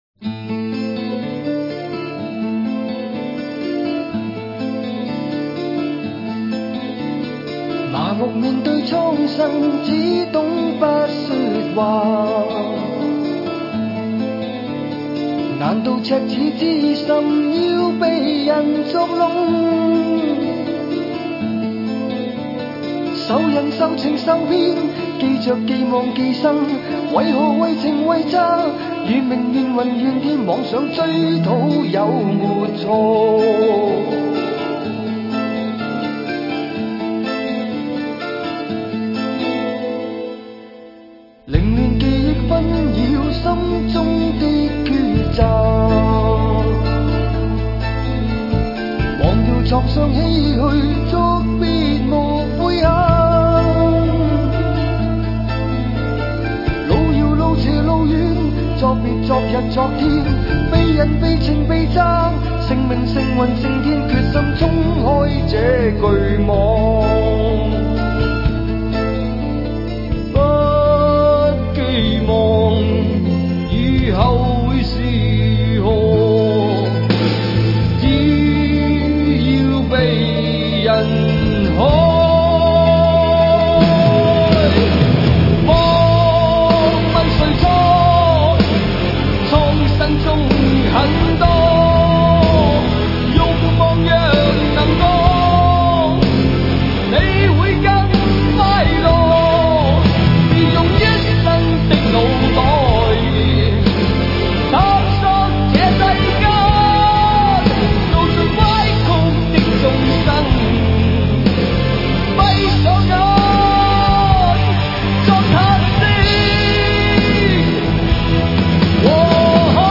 主唱